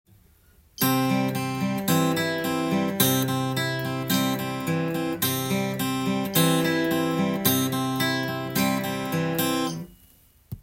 ピックと指弾き強化【ギターで16分音符のアルペジオ練習】
コードはCでアルペジオパターンを譜面にしてみました。
④～⑥はピック弾き＋中指の指弾きも出てくるので